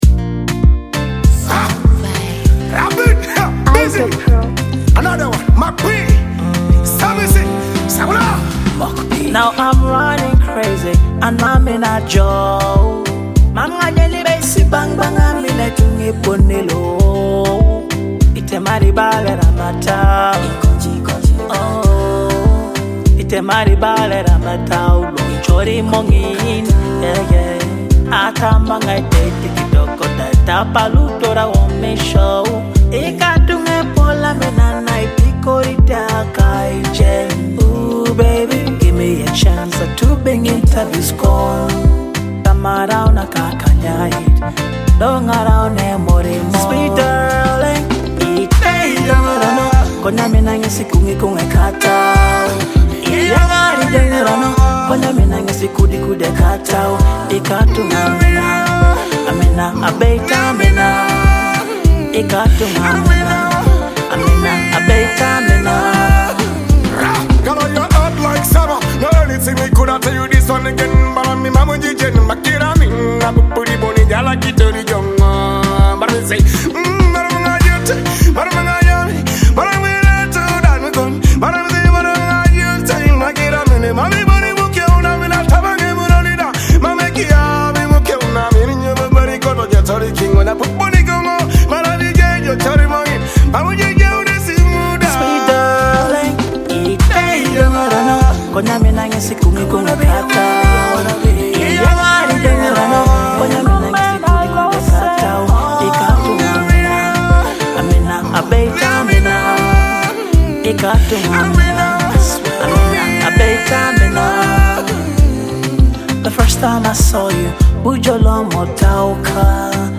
the ultimate love anthem with authentic African vibes.